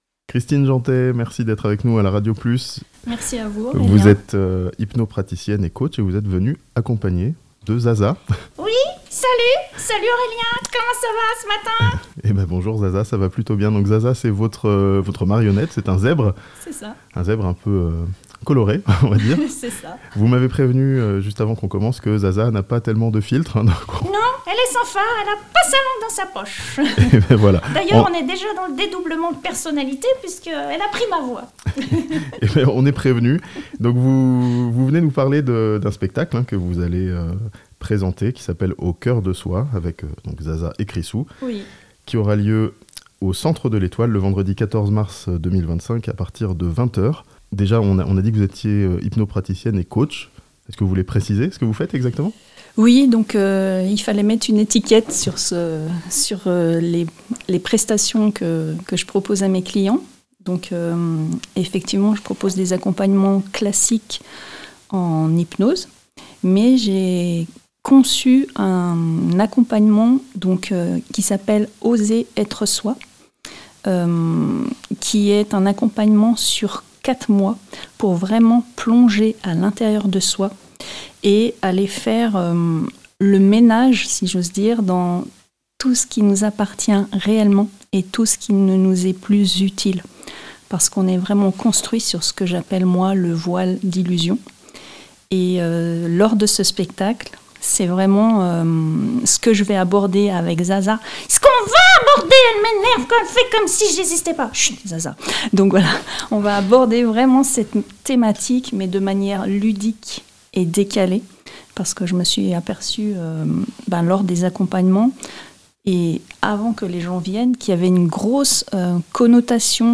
A Thonon, un spectacle interactif pour plonger au coeur de soi (interview)